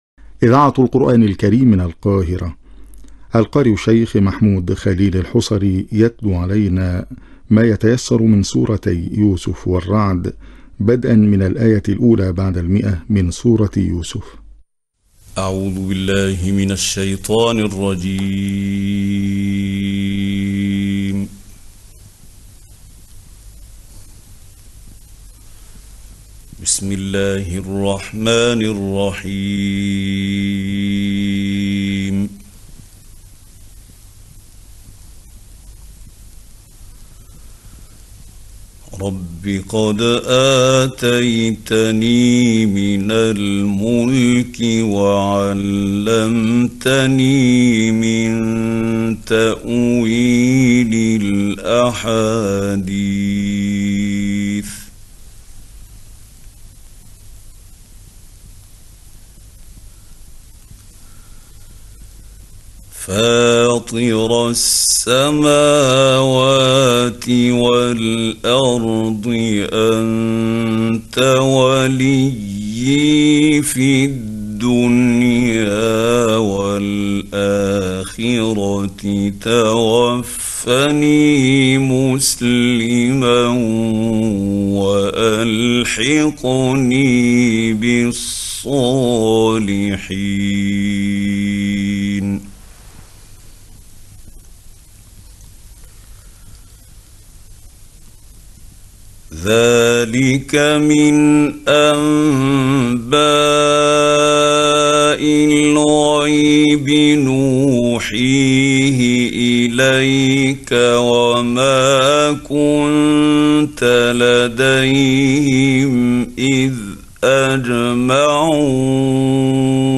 نوع التلاوة  تجويد نادر ورائع
المكان  الاذاعة المصرية